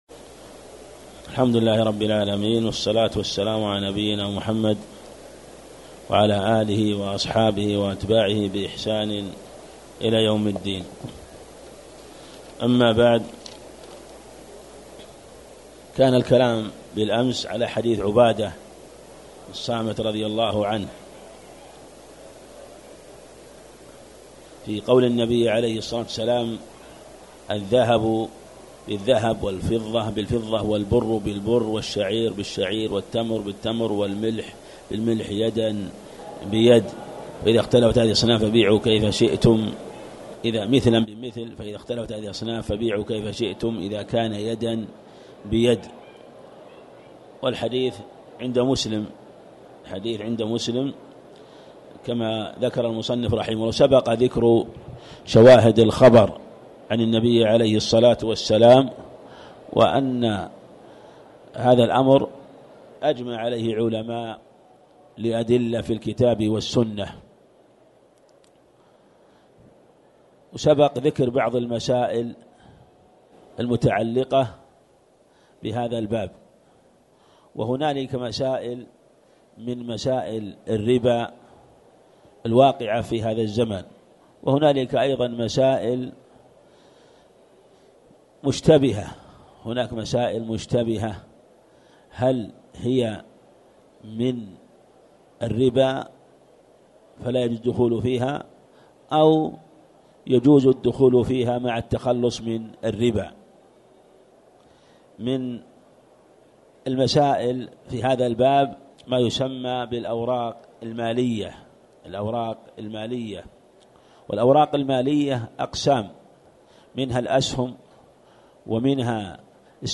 تاريخ النشر ٤ رمضان ١٤٣٨ هـ المكان: المسجد الحرام الشيخ